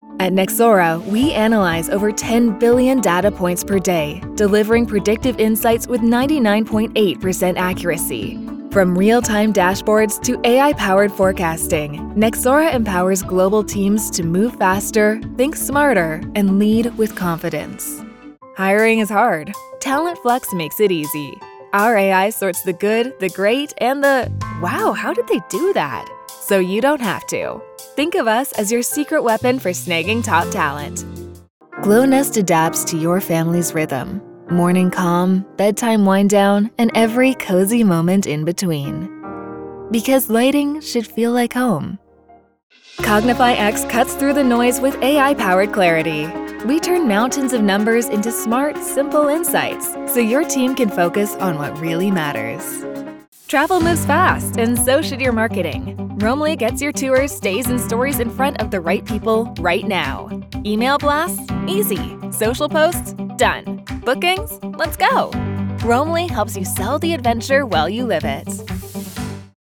0724Explainer_Demo.mp3